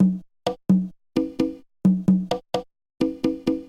标签： 130 bpm Drum And Bass Loops Drum Loops 636.31 KB wav Key : Unknown
声道立体声